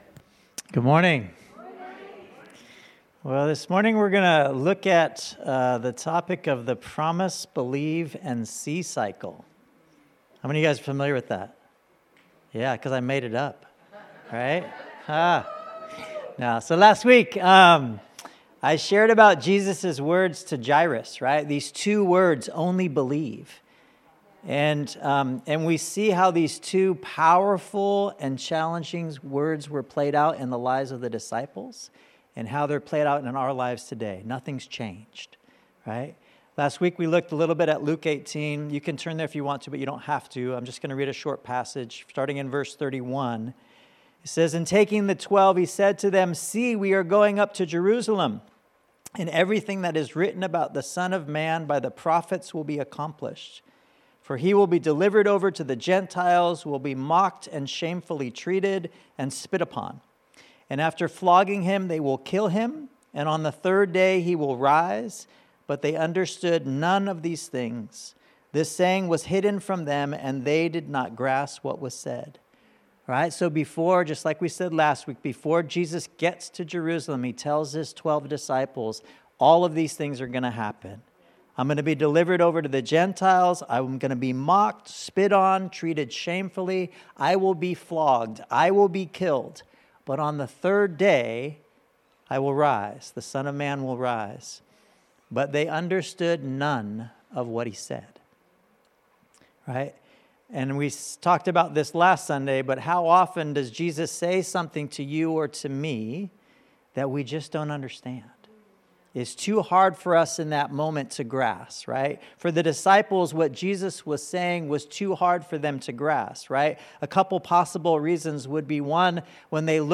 1 The Gift Of Diverse Kinds Of Tongues And Interpretation Of Tongues - 01 Sept 2024 39:36 Play Pause 21d ago 39:36 Play Pause Αναπαραγωγή αργότερα Αναπαραγωγή αργότερα Λίστες Like Liked 39:36 Listen to a sermon by Bishop Mosa Sono, preached on the 1st of September, 2024, titled: The Gift Of Diverse Kinds Of Tongues And Interpretation Of Tongues.